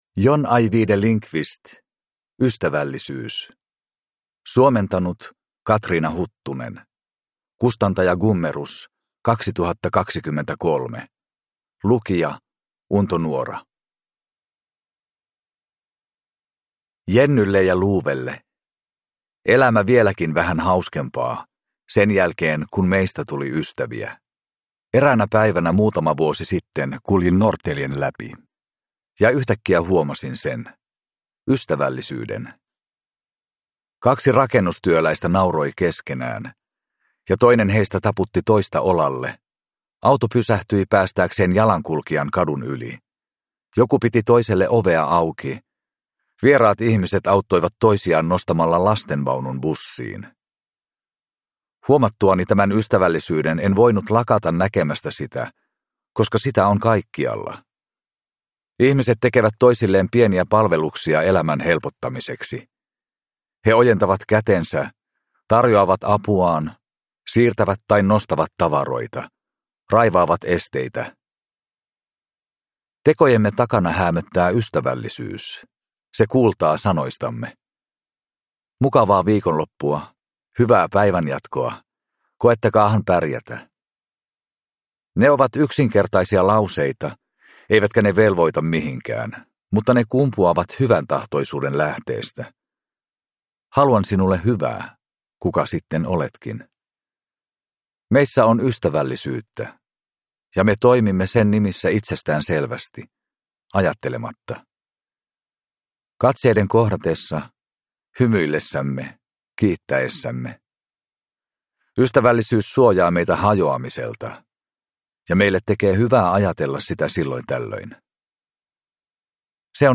Ystävällisyys – Ljudbok